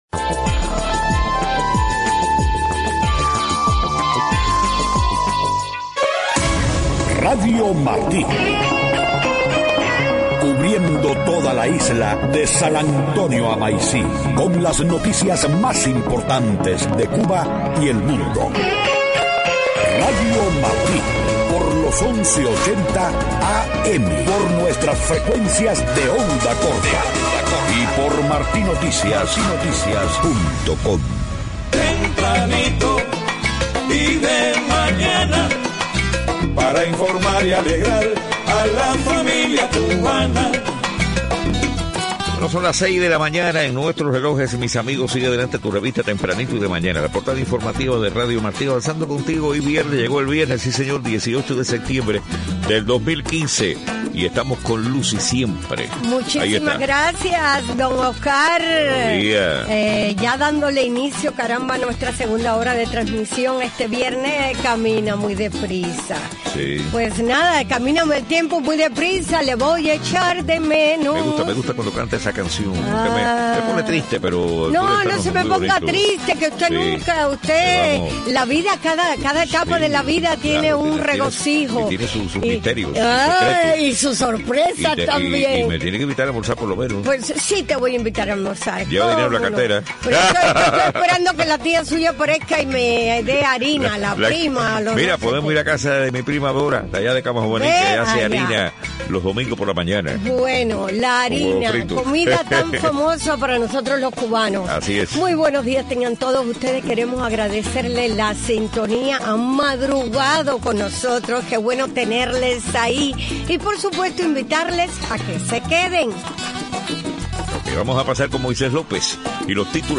6:00 a.m. Noticias: Gobierno cubano manipula los salarios de los médicos en misiones extranjeras, según informe. EEUU destaca los grandes retos para el acceso a la Internet en Cuba.